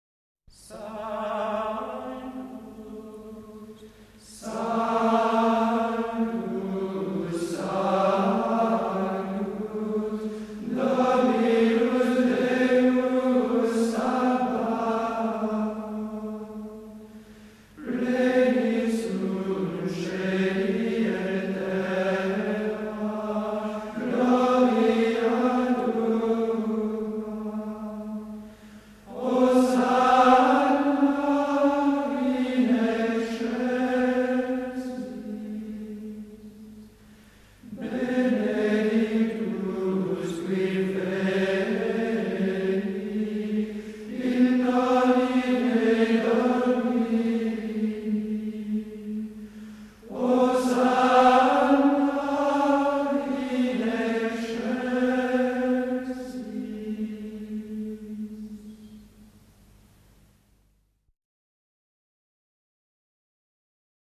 Ce Sanctus est dépourvu d’éclat, il est tout intérieur. Il faut donc le chanter à mi-voix, avec beaucoup de douceur, comme dans l’intimité de la louange.
Les trois Sanctus sont très sobres : un neume sur la syllabe accentuée, une finale très simple, le tout enroulé mélodiquement autour de la tonique Mi.
On peut exprimer un léger crescendo d’un Sanctus à l’autre, mais tout cela doit rester très doux, très contemplatif.
Les deux hosánna sont identiques et représentent le sommet intensif de toute la pièce.
Sanctus-10-Solesmes.mp3